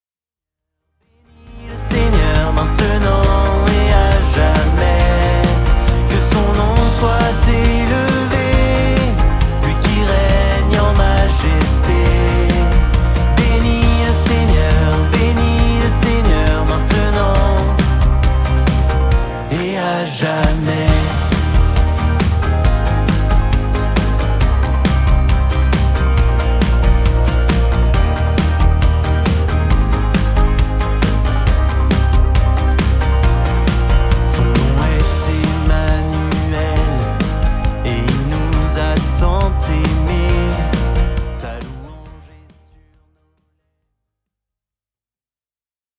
louanges